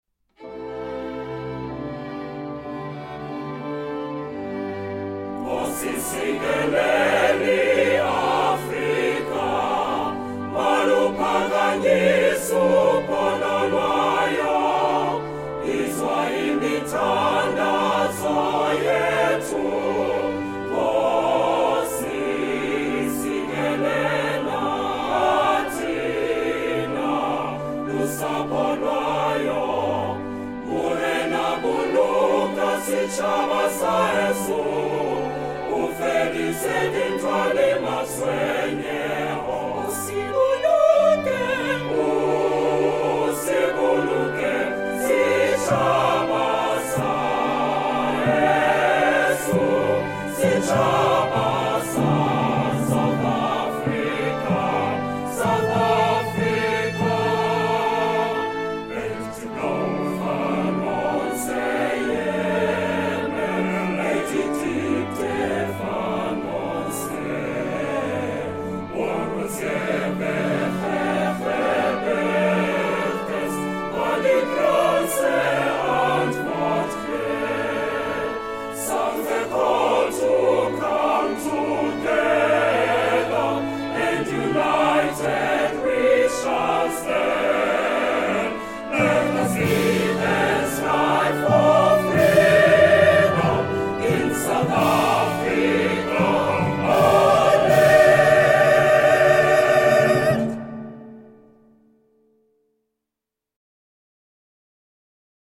National Anthem sung by Choir and Orchestra
02 National Anthem sung by choir and orchestra.mp3